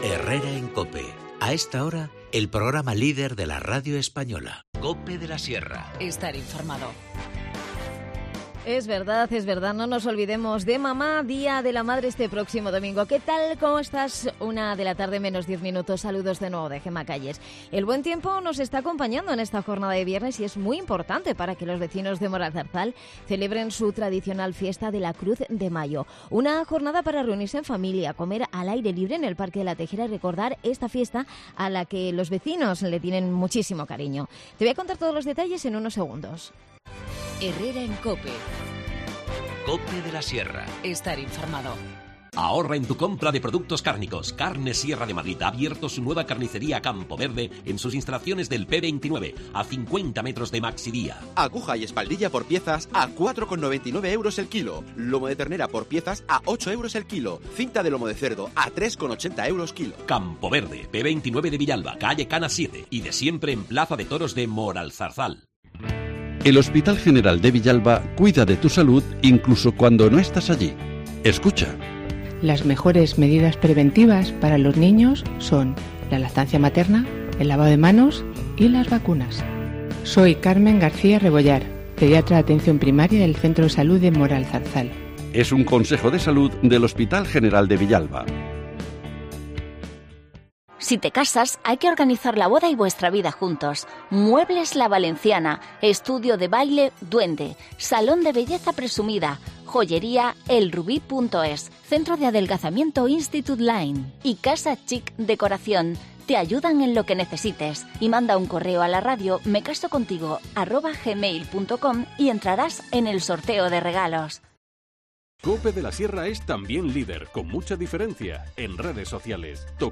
Gema Duque, concejal de Cultura en Moralzarzal, nos habla de la tradicional fiesta de la Cruz de mayo que se celebra este viernes y nos da todos los detalles sobre el archivo municipal digital, la nueva herramienta que disponen ahora los vecinos para conocer la historia y las gentes de la localidad.